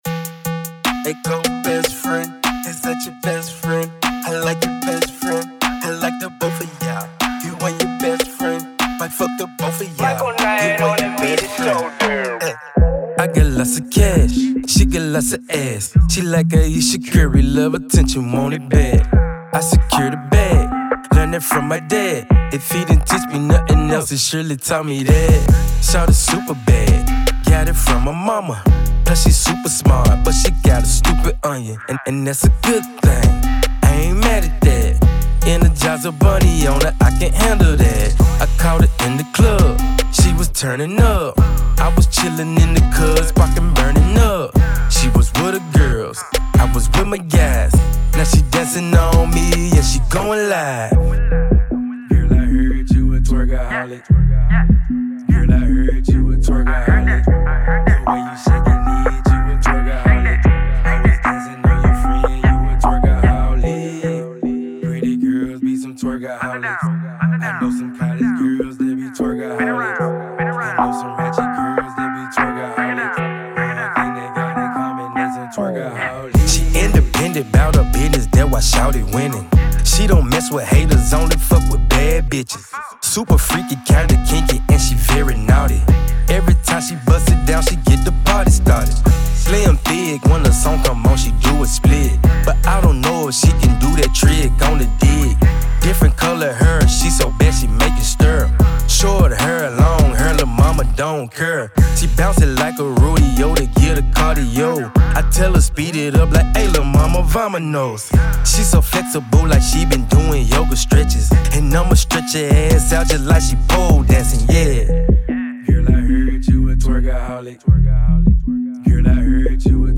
Hiphop
an up tempo, fun dance/twerk record